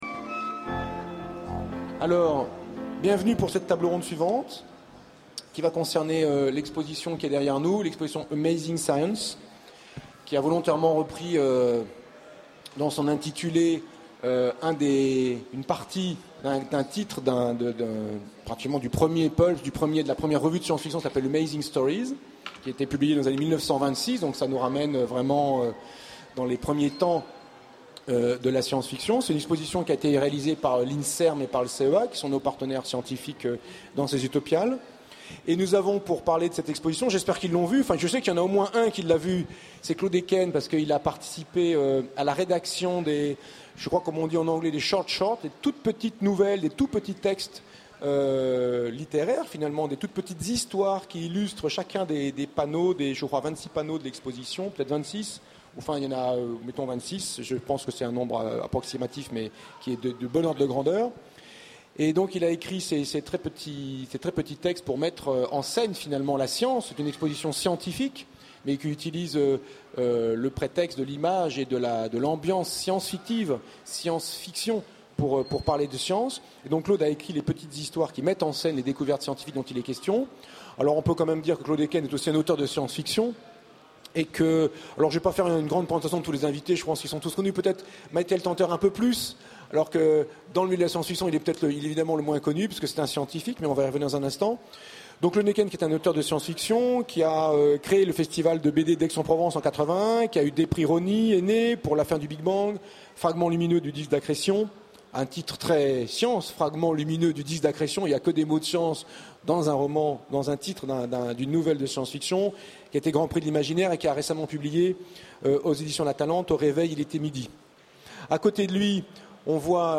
Utopiales 12 : Conférence Amazing Science, l’exposition